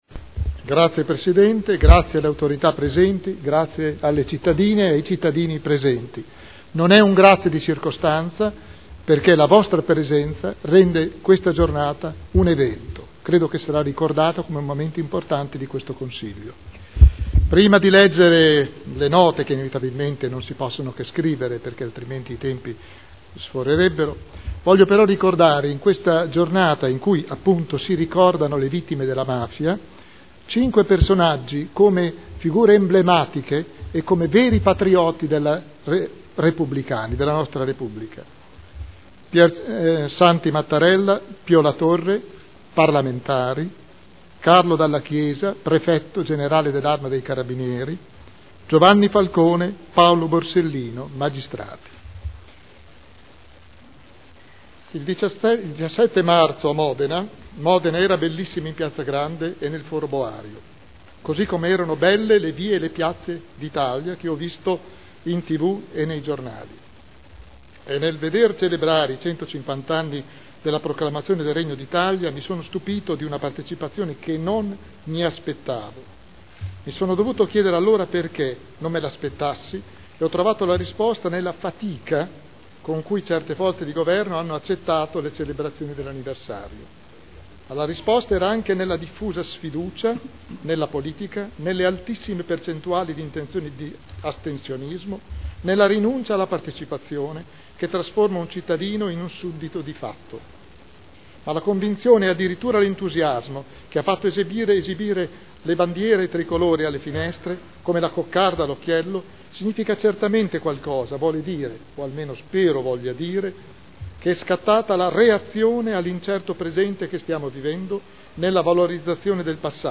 Seduta solenne 150° anniversario dell'unità d'Italia. Interventi dei gruppi consiliari